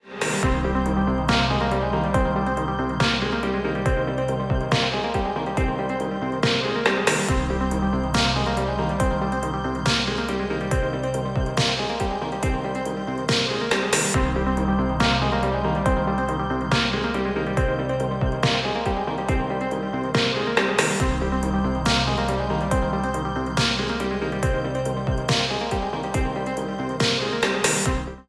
Here’s a little demo of a loop from a track. Each 4 bars I switch between the DAW version and the Bebe Cherie version. I’ve tried to volume match each.
I adjusted the gain on each channel so the red LED on Bebe just about tickles on at times and then adjusted the level knob to re-balance each track. (DAW version is first).
So this is just the effect of the gain saturation and analogue summing.
The stereo field sounds nicer to me after going through the Bebe.
In my opinion, the Wet sounds more open and rounded vs. the DAW Dry version which feels slightly more closed.